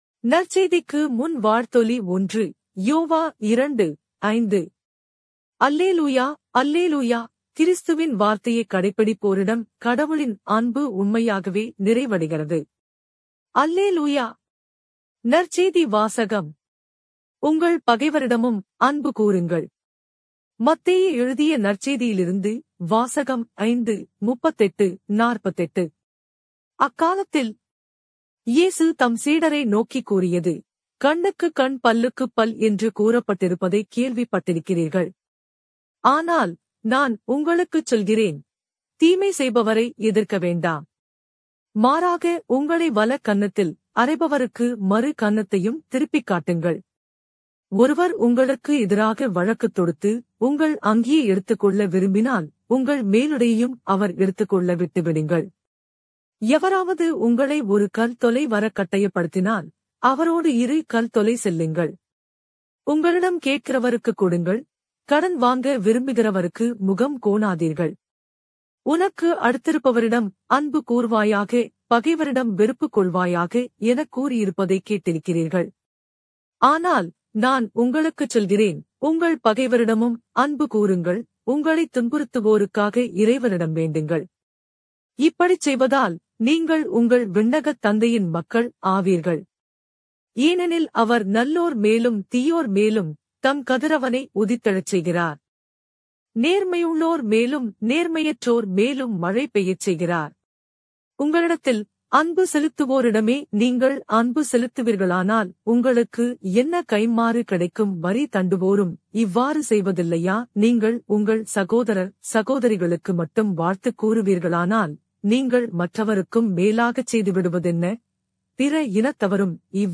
நற்செய்தி வாசகம்